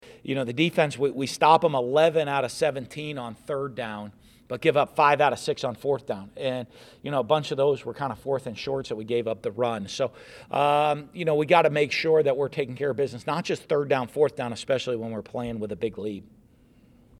In his press conference today, Dan Mullen said the biggest area for improvement on the defensive side of the ball is preventing fourth-and-short scenarios.